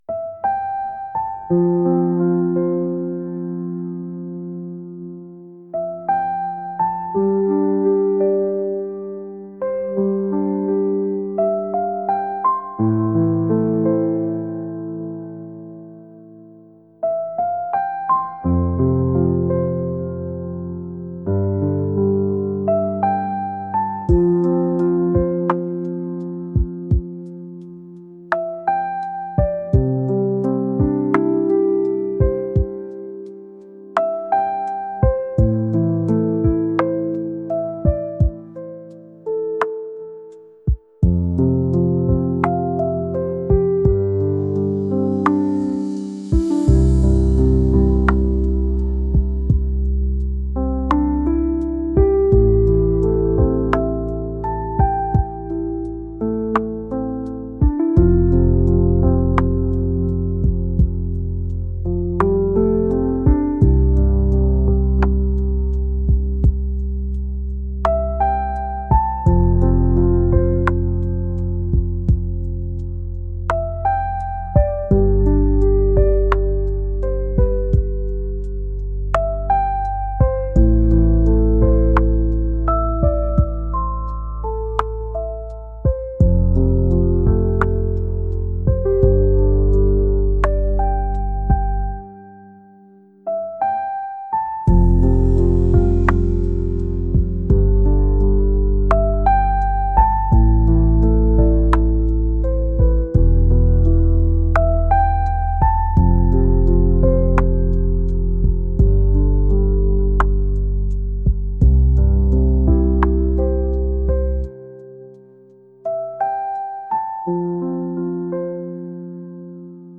ambient